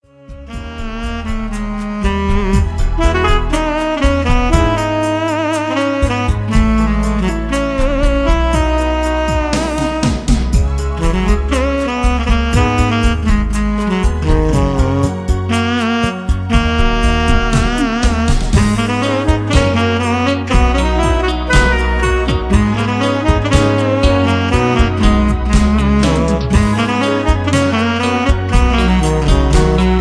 Relaxed Instrumental composition
Tags: pop, soundtrack